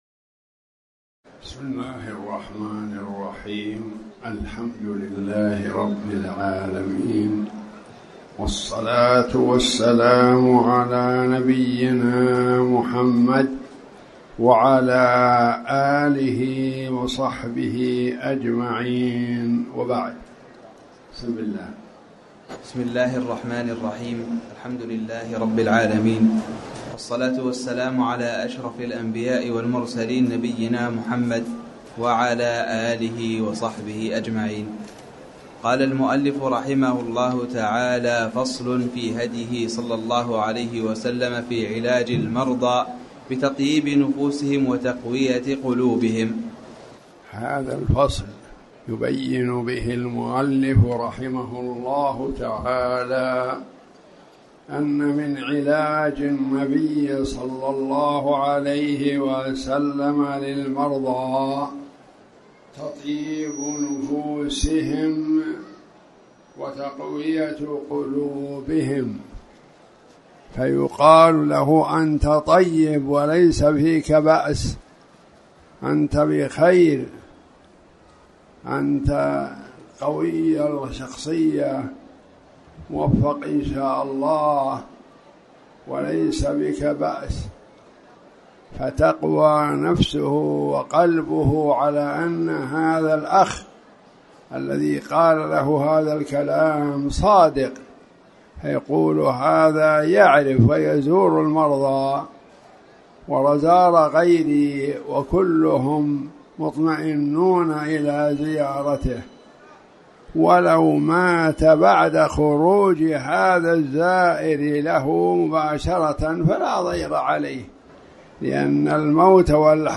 تاريخ النشر ١٣ رجب ١٤٣٩ هـ المكان: المسجد الحرام الشيخ